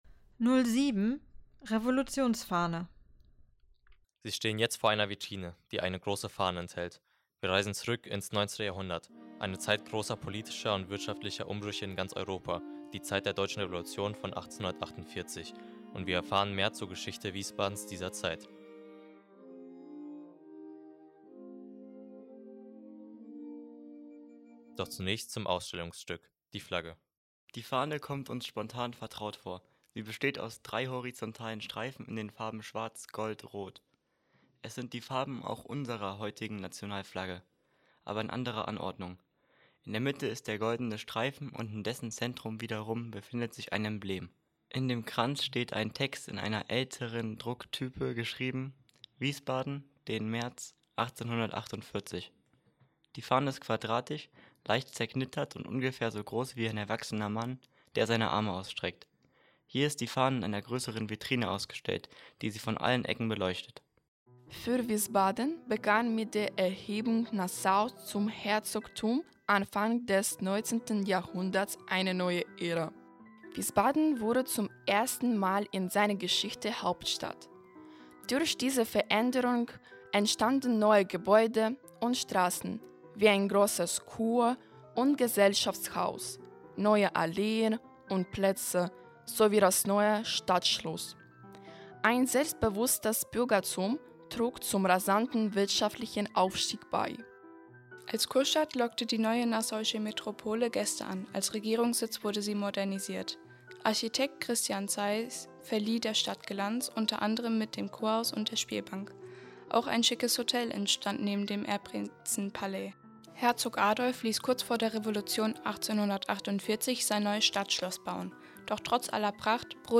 For blind and visually impaired people, there is an audio guide in the sam that complements the floor guidance system.
Audio guide - Station 7 Revolutionary flag